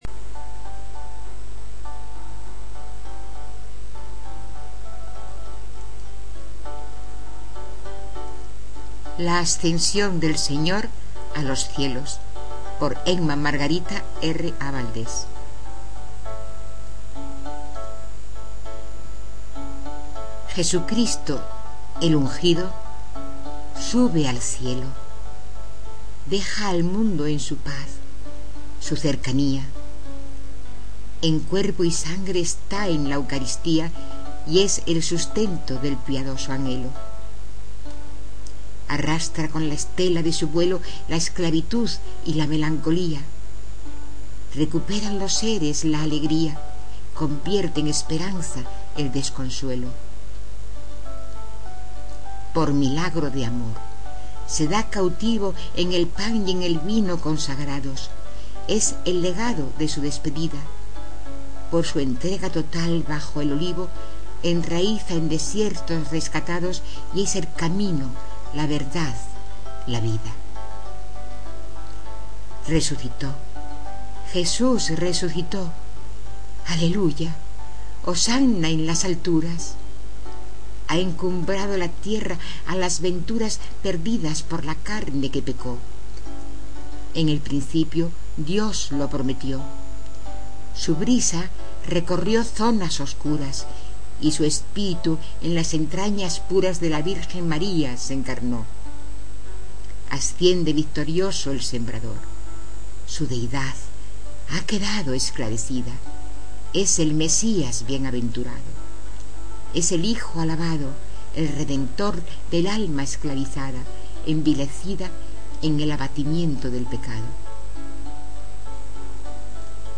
Poesías